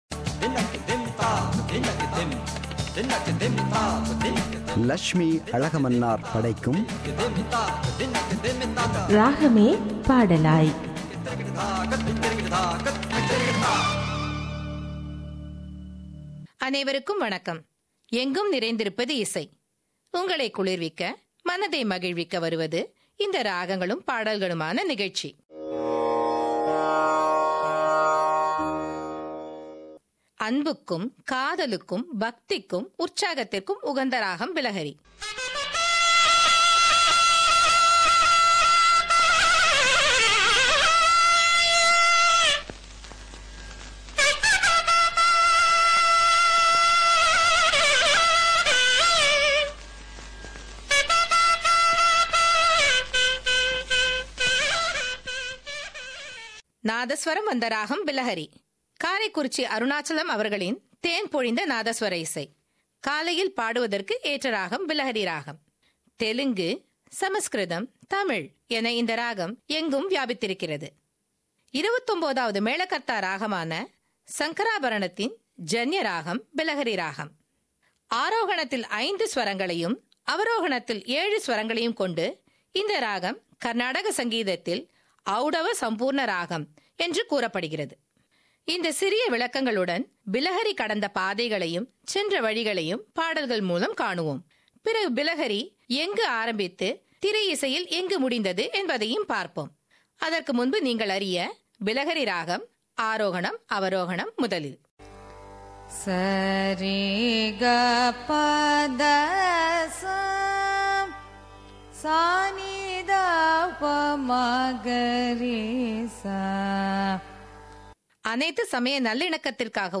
“Ragame Padalaay” – Musical Program –Part 9